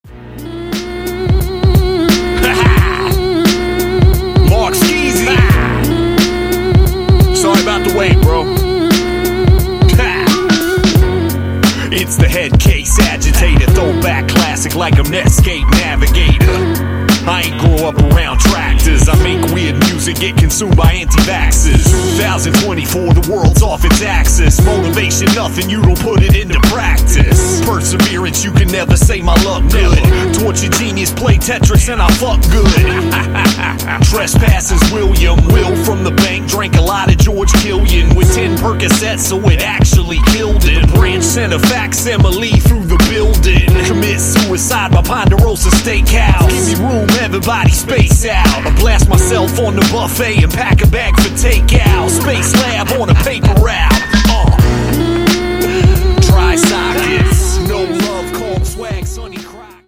high grade guest raps